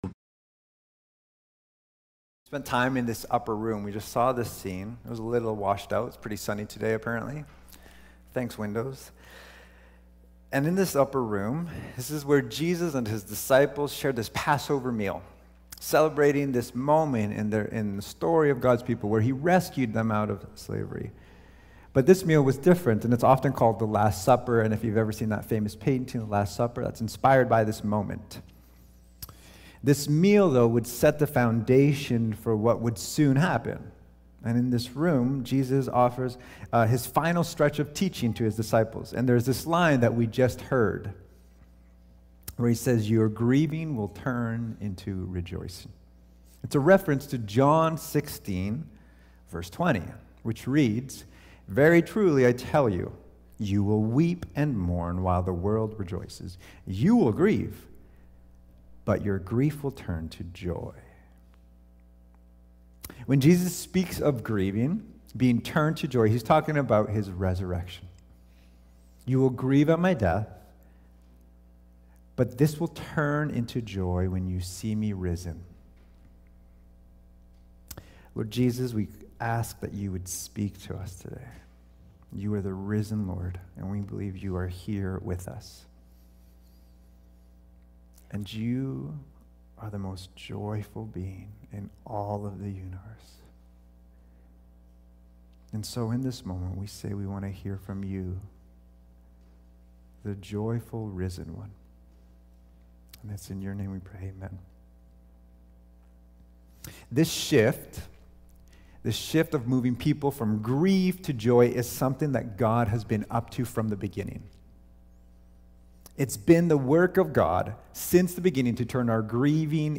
Cascades Church Sermons The Joy of the Risen Lord Play Episode Pause Episode Mute/Unmute Episode Rewind 10 Seconds 1x Fast Forward 30 seconds 00:00 / 30:44 Subscribe Share Apple Podcasts RSS Feed Share Link Embed